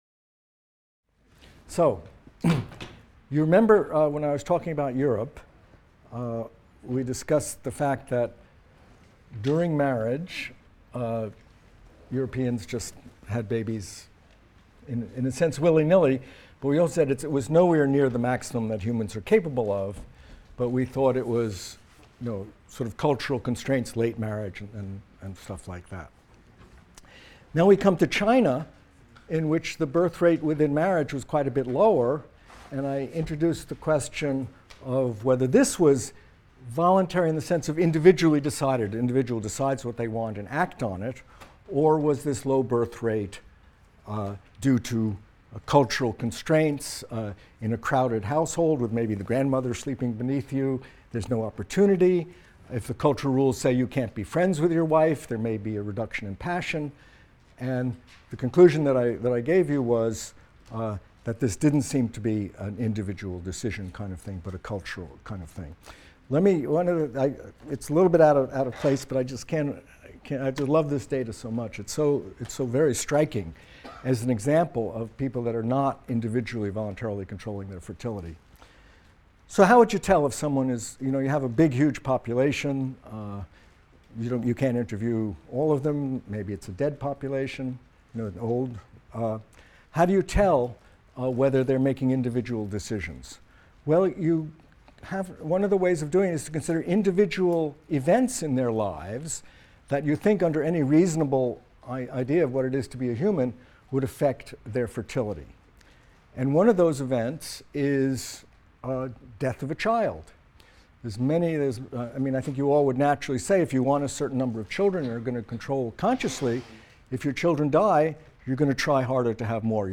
MCDB 150 - Lecture 18 - Economic Impact of Population Growth | Open Yale Courses